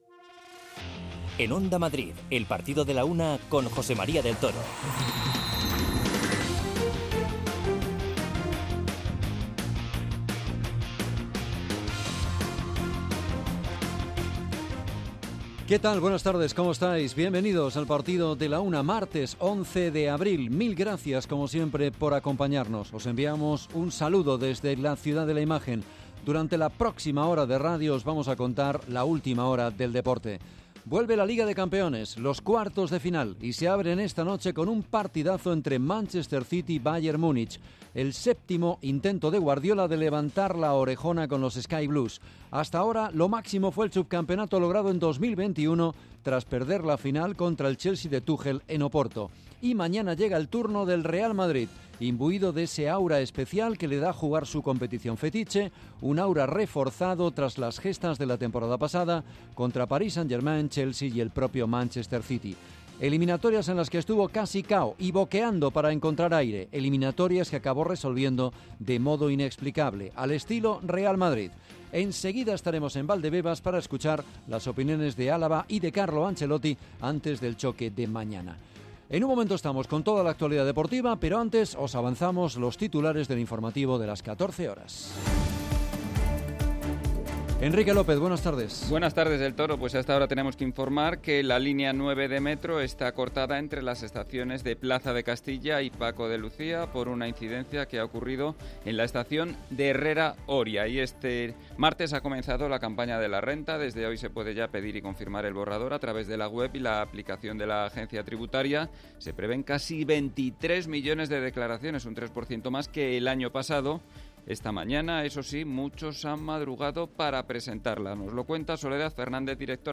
Comenzamos conectando en directo con Valdebebas para escuchar la comparecencia de prensa de Carlo Ancelotti antes del partido frente al Chelsea.